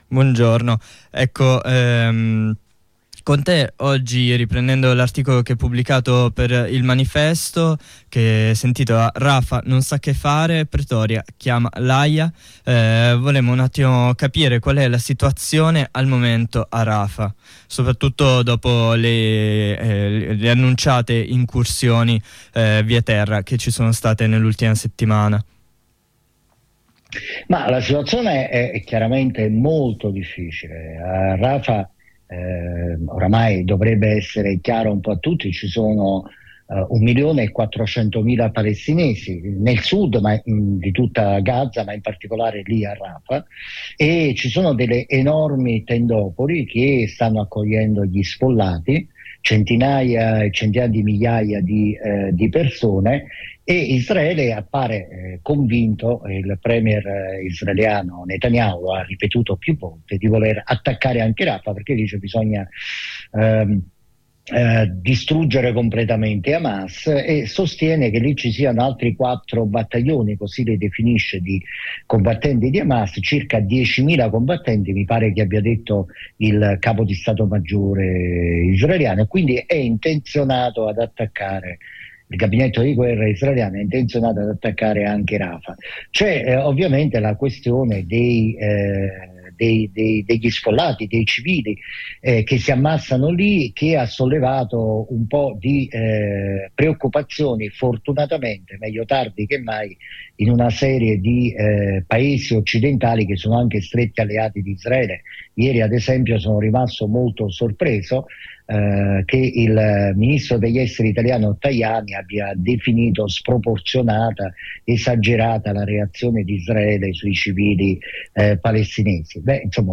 Di seguito l’intervista